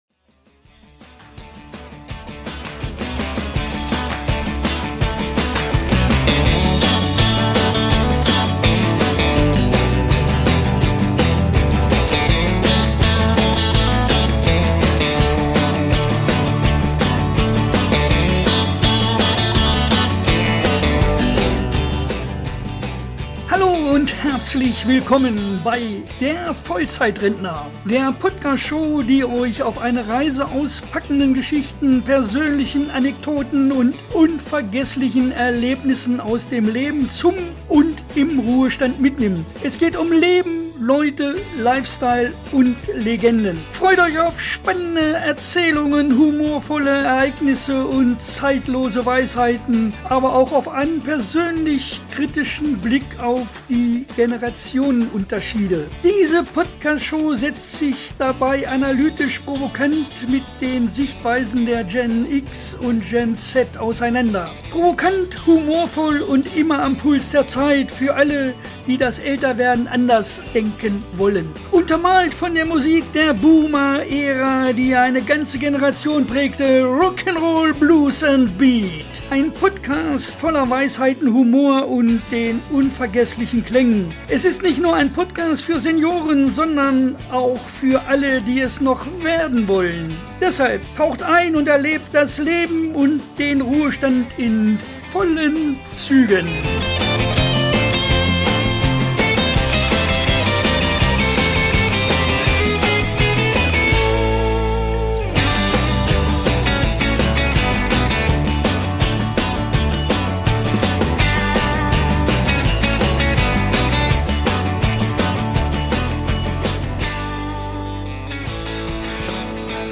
Energiegeladen! Kompromisslos! Wild! Ein Soundtrack , der euch die Haare aufstellt – und die Füße zum Tanzen zwingt!